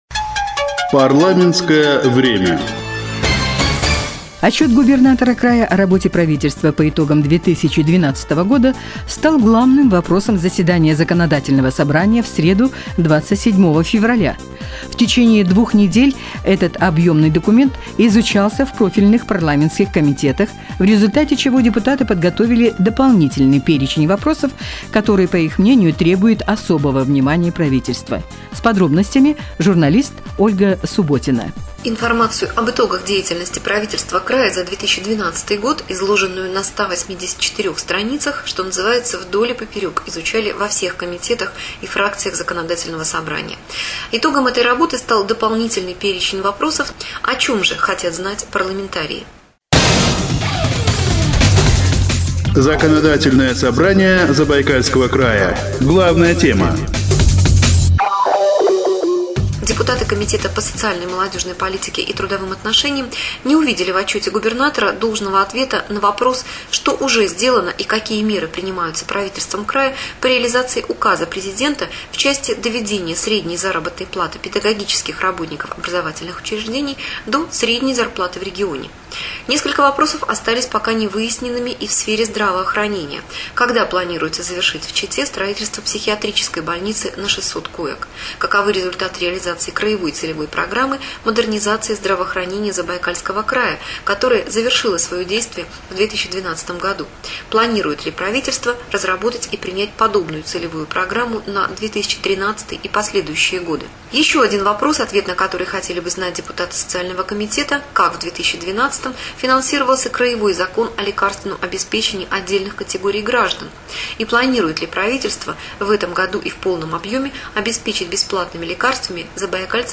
Информационный сюжет "Отчет-2012: у депутатов есть вопросы"